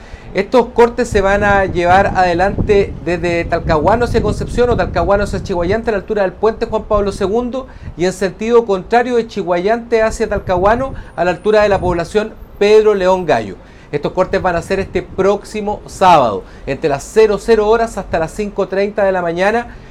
El seremi de Transportes, Patricio Fierro, detalló que “estos cortes se van a llevar adelante desde Talcahuano hacia Chiguayante, a la altura del Puente Juan Pablo II, y en sentido contrario, de Chiguayante a Talcahuano, a la altura de la población Pedro León Gallo”.